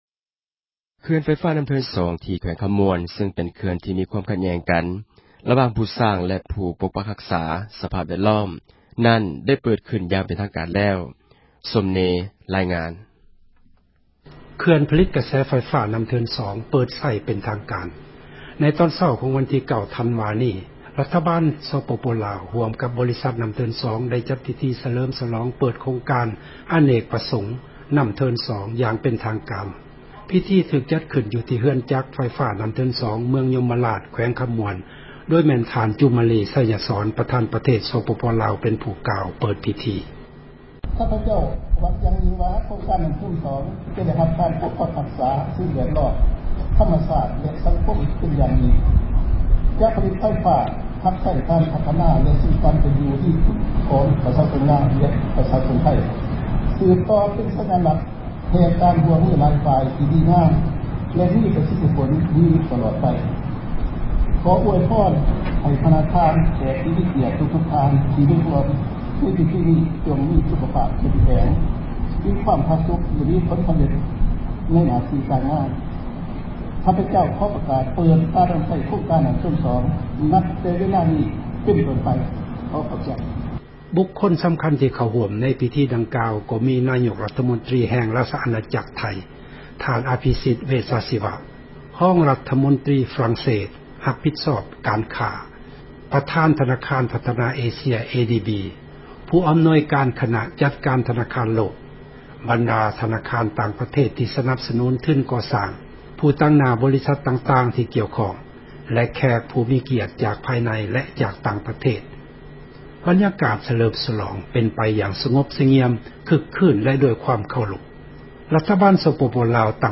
ພິທີເປີດເຂື່ອນນໍ້າເທີນ 2
ພິທີຖືກ ຈັດຂື້ນ ຢູ່ທີ່ເຮືອນ ຈັກໄຟຟ້າ ນໍ້າເທີນ 2 ເມືອງຍົມມະລາດ ແຂວງຄໍາມ່ວນ ໂດຍແມ່ນ ທ່ານ ຈູມມາລີ ໄຊຍະສອນ ປະທານປະເທດ ສປປ ລາວ ເປັນຜູ້ກ່າວ    ເປີດພິທີ: ສຽງ....